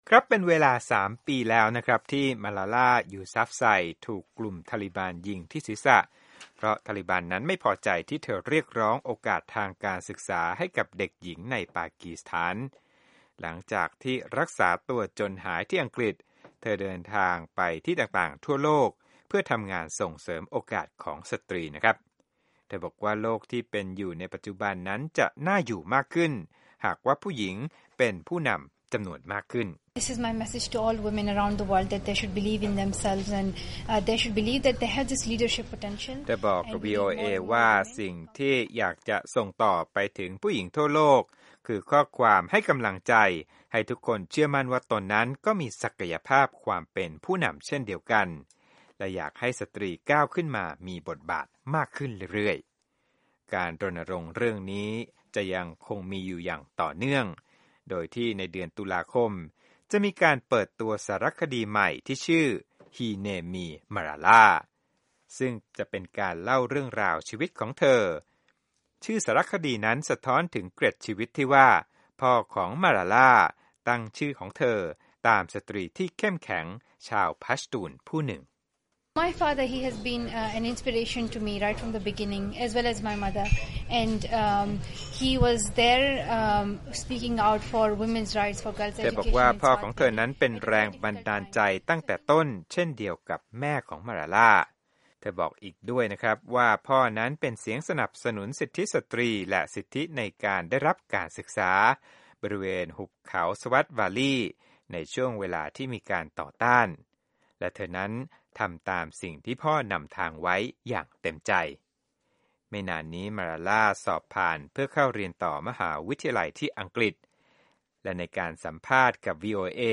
สัมภาษณ์ Malala Yousafzai